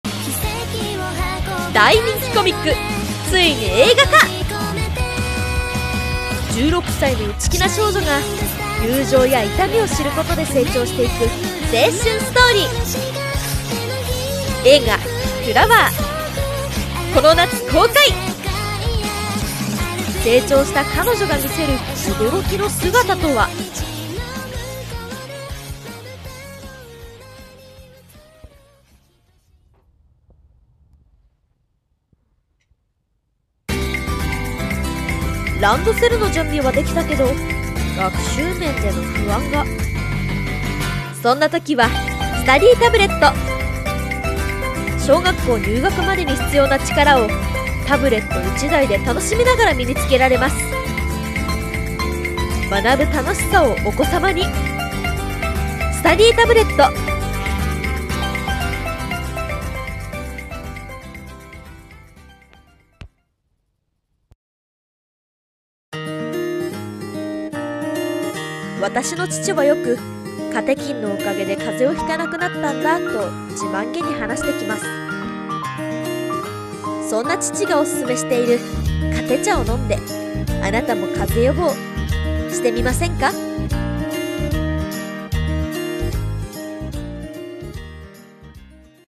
ラジオCM風【台本】一人声劇